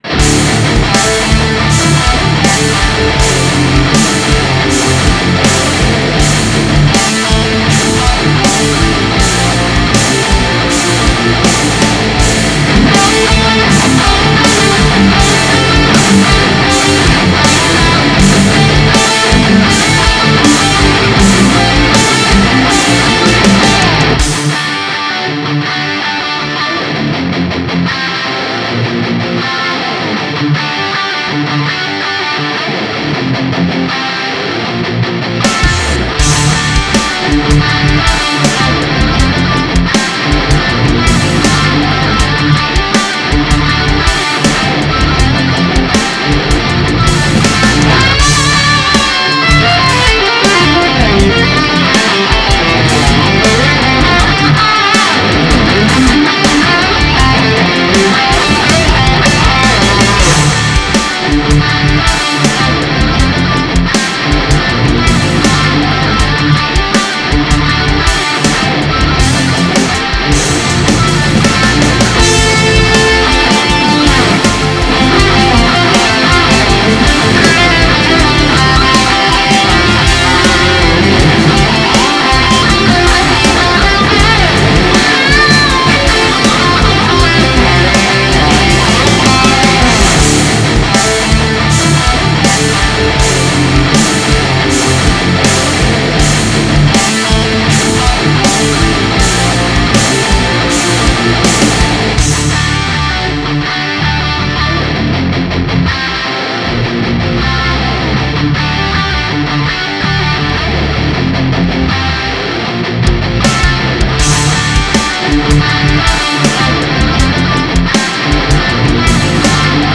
(strumentale)